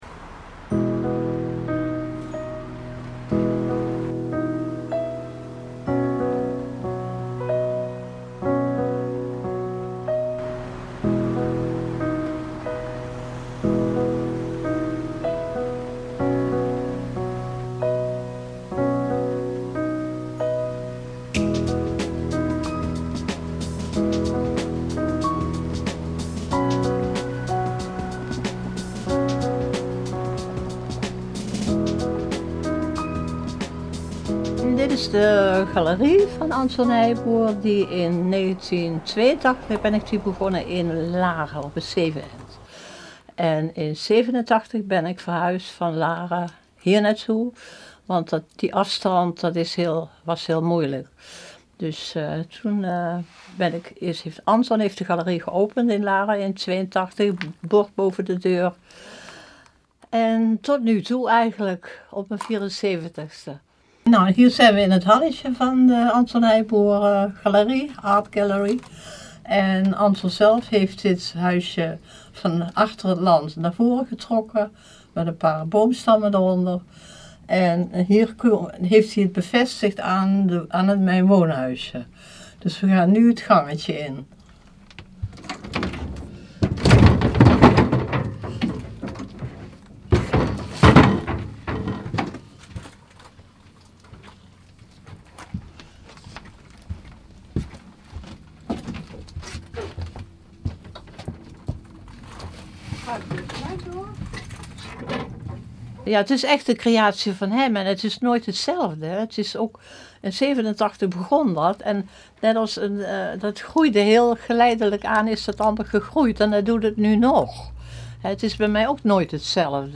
Interview
De video is een verkorte versie van het gehele interview.